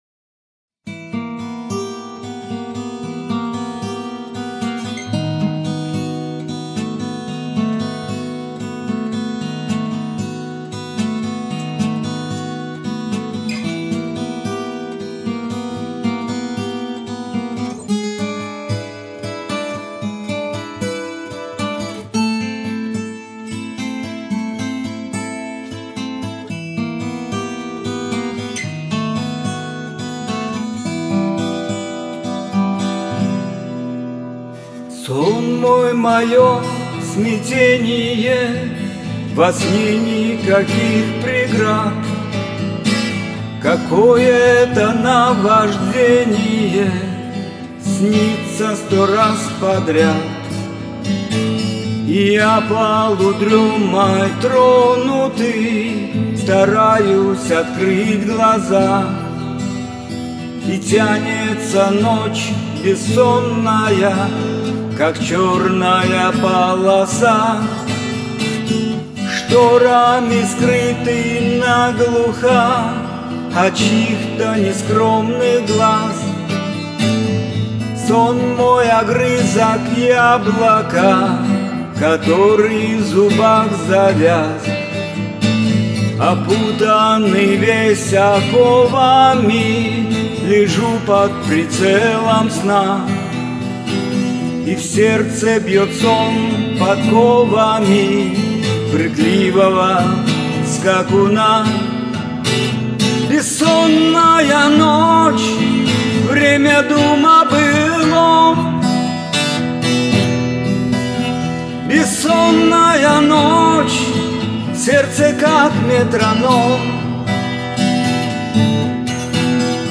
гитара
гитарную версию этой песни